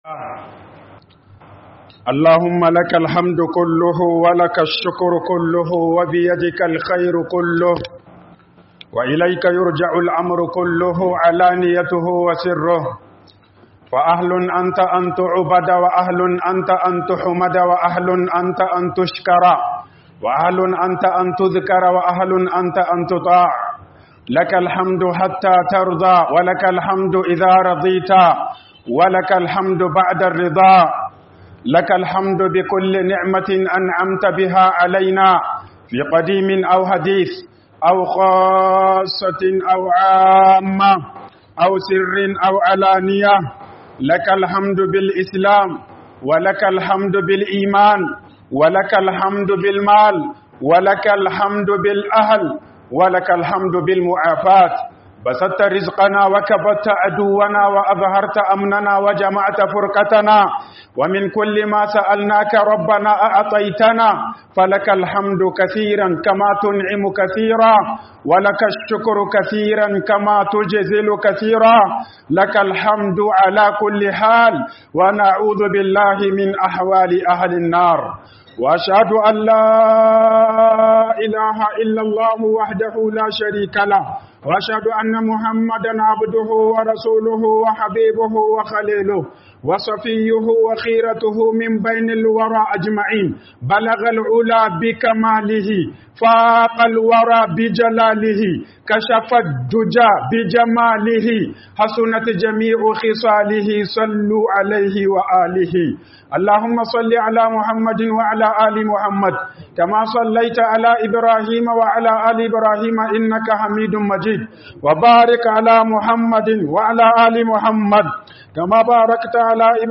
HUƊUBOBIN JUMA'A